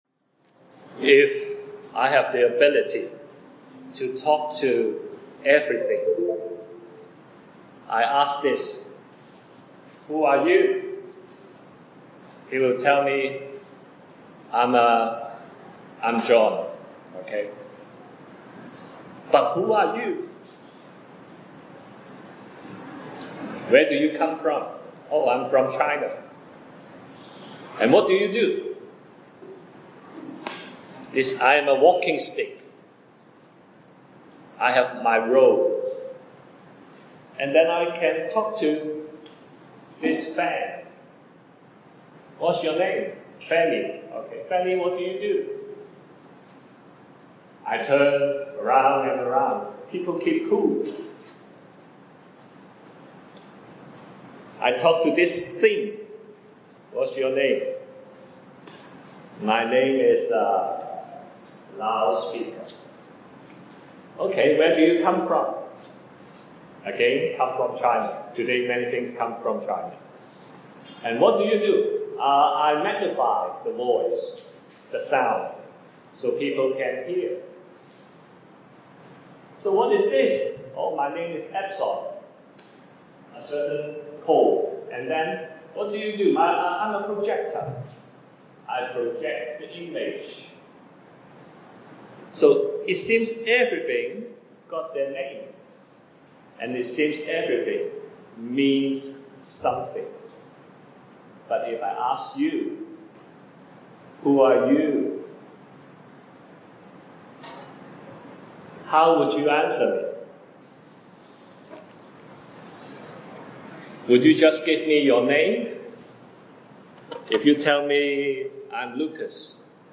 Scripture reference: Genesis1:1, Genesis 3:15, 2 Samuel 7:13, Matthew 1:21-22 A Gospel message about finding our identity, our origin, our role in creation, the effect of sin and the redemption of Christ.